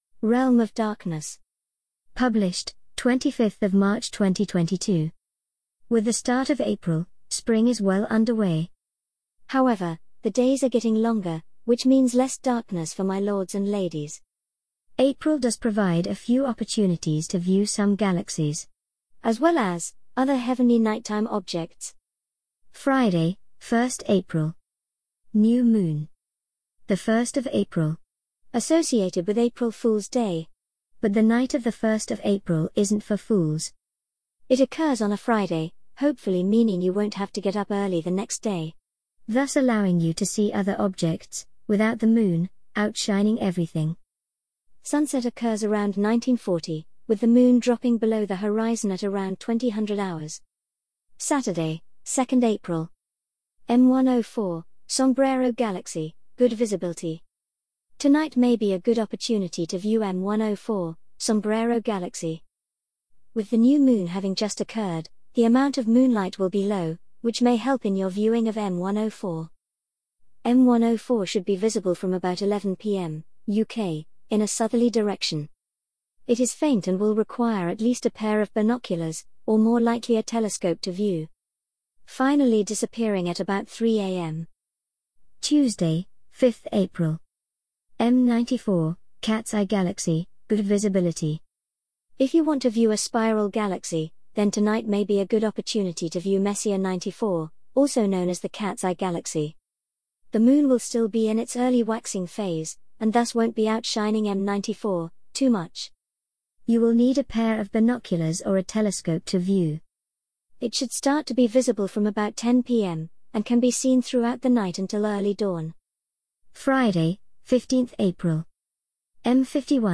An audio reading of the Realm of Darkness April 2022 Article